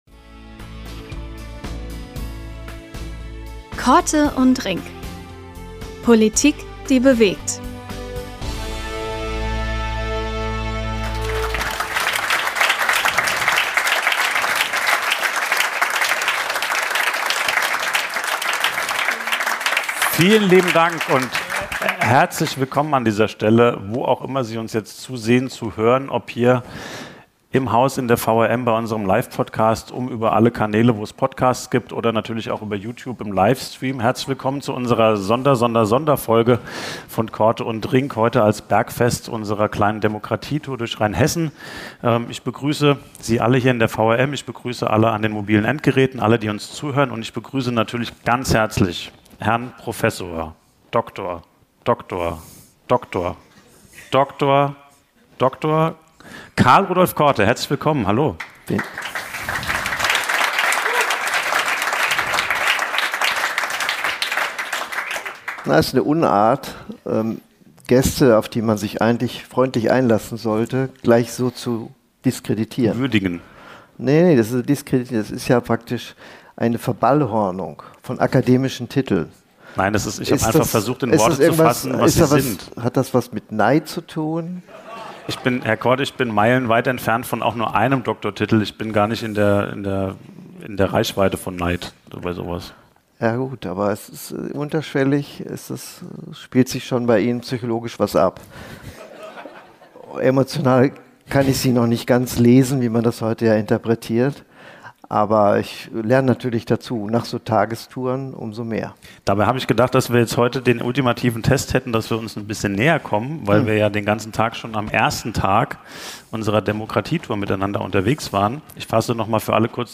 Bergfest unserer Demokratie-Tour: Chefredakteur und Politikwissenschaftler lassen den Tag bei einem besonderen Podiumsgespräch auf dem Mainzer Lerchenberg ausklingen – vor Live-Publikum.